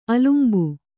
♪ alumbu